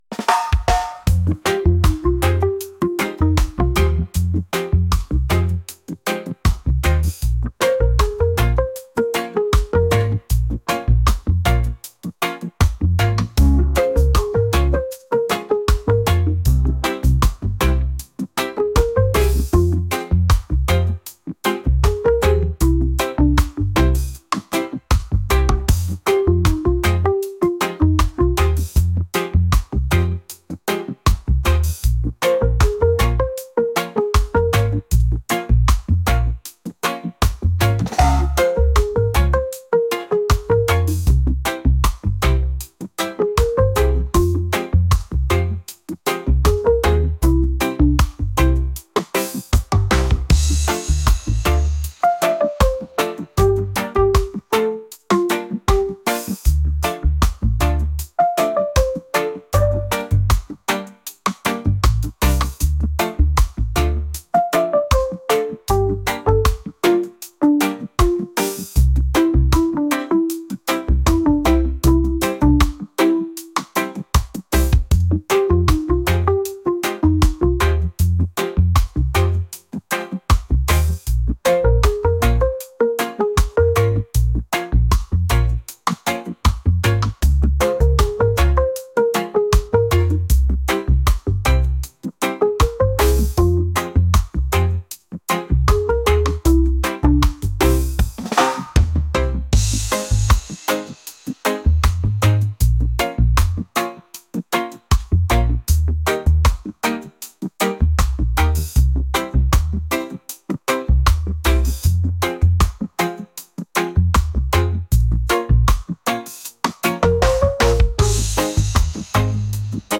reggae | latin | lofi & chill beats